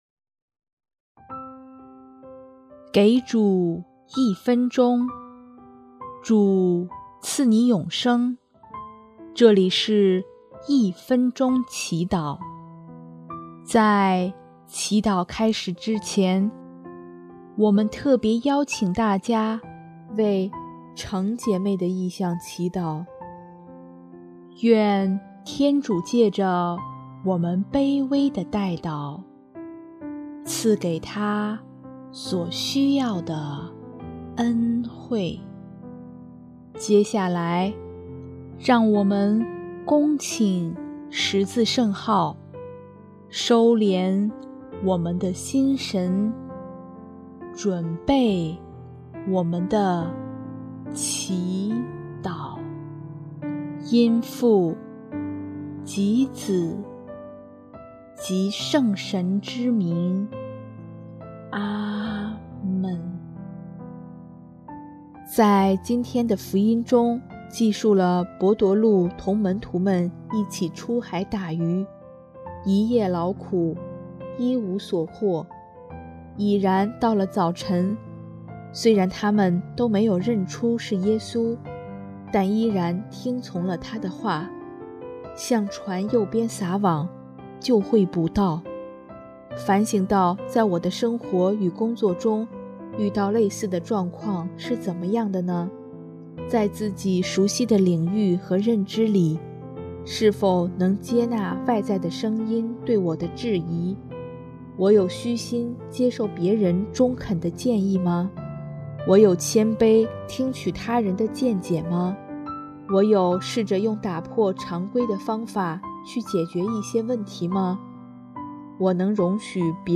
首页 / 祈祷/ 一分钟祈祷
音乐：第四届华语圣歌大赛参赛歌曲《活出你模样》